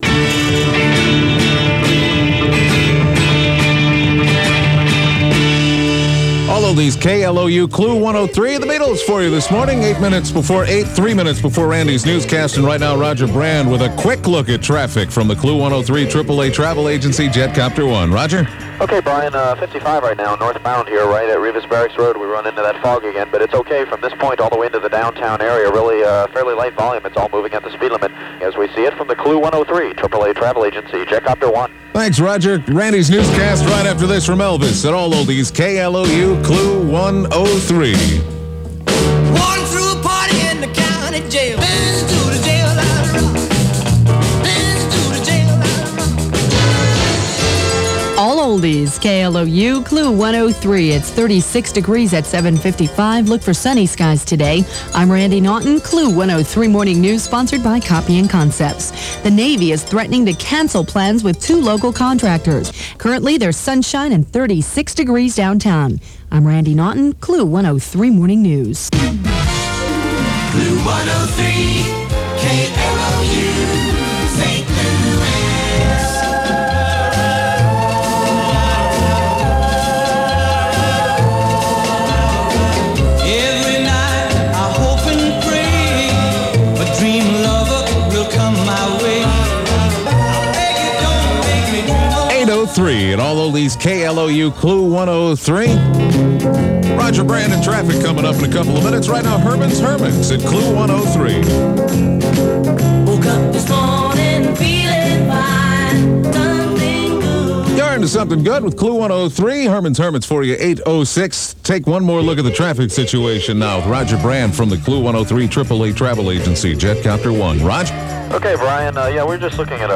Aircheck